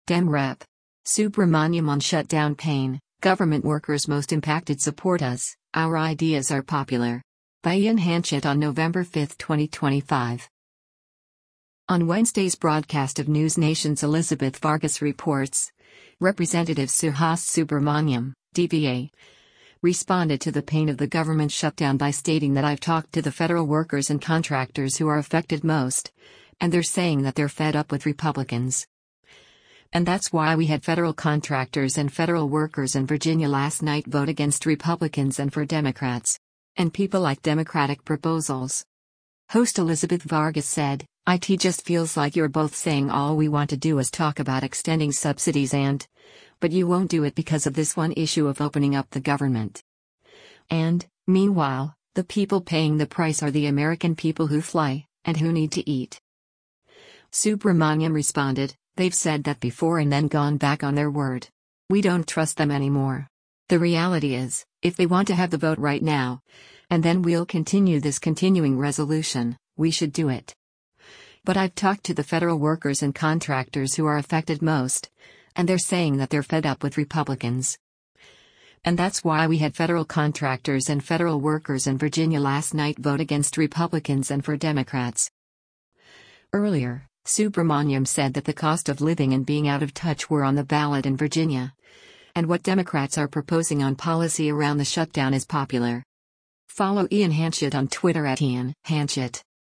On Wednesday’s broadcast of NewsNation’s “Elizabeth Vargas Reports,” Rep. Suhas Subramanyam (D-VA) responded to the pain of the government shutdown by stating that “I’ve talked to the federal workers and contractors who are affected most, and they’re saying that they’re fed up with Republicans. And that’s why we had federal contractors and federal workers in Virginia last night vote against Republicans and for Democrats.” And people like Democratic proposals.